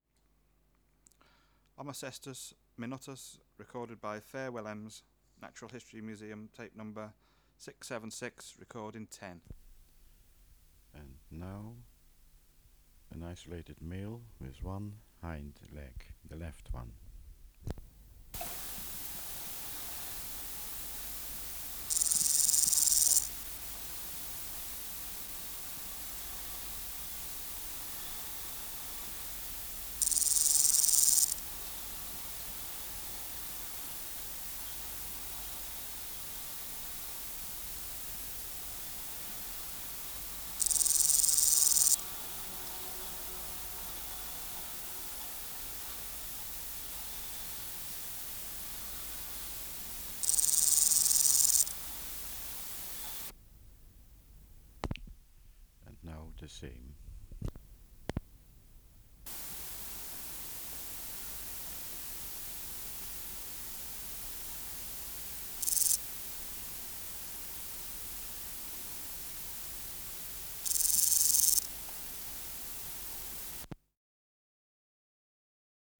Species: Omocestus (Omocestus) minutus